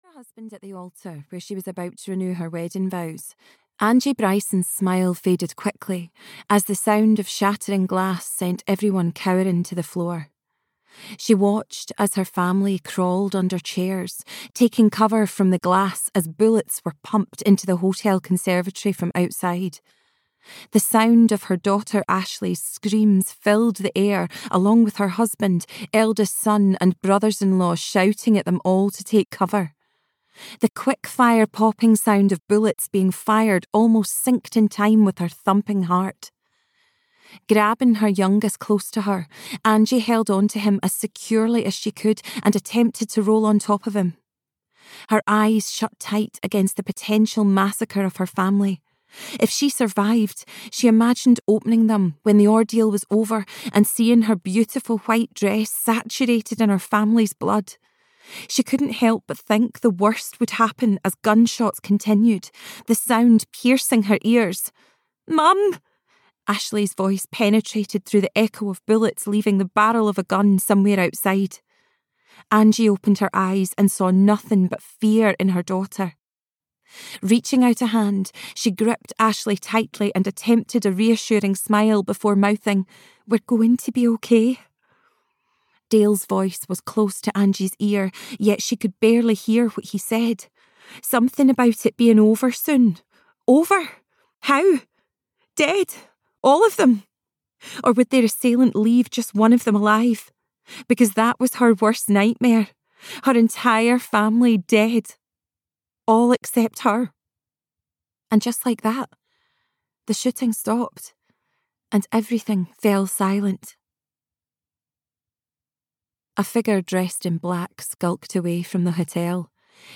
The Mother (EN) audiokniha
Ukázka z knihy